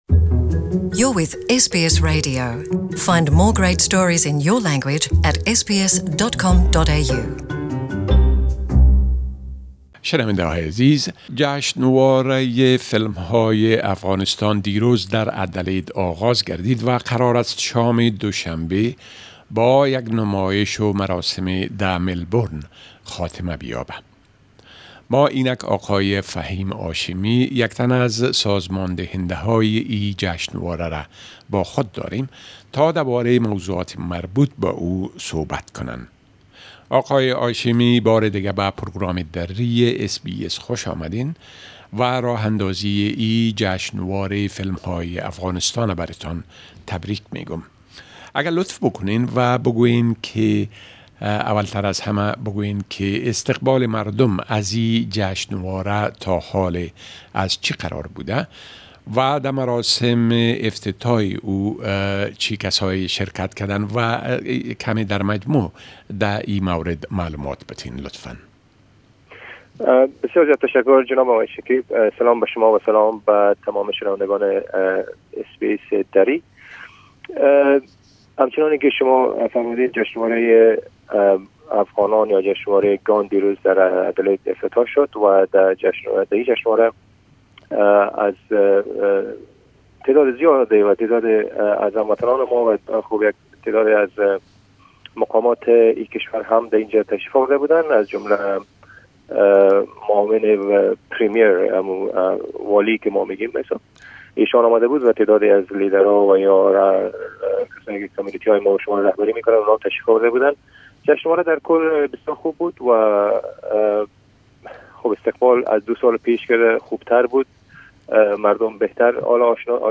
Ghan (Afghan) International Film Festival is currently underway in Australia. We had an interview